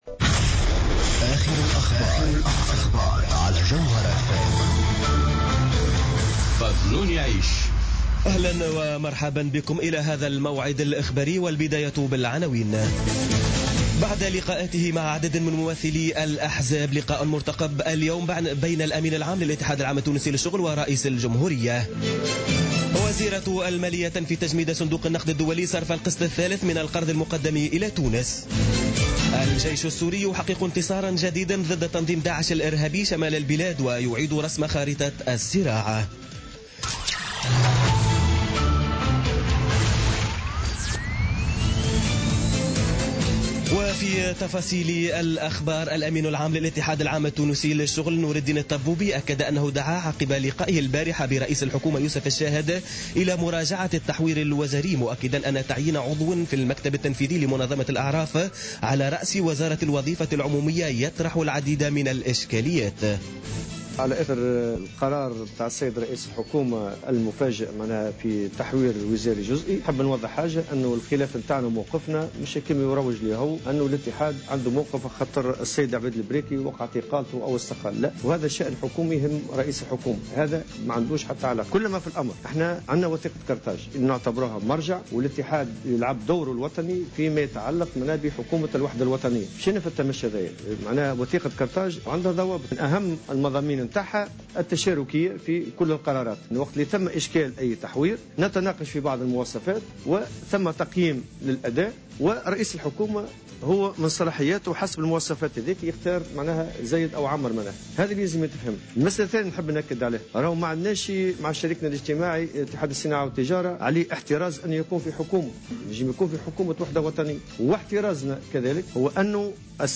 نشرة أخبار منتصف الليل ليوم الثلاثاء 28 فيفري 2017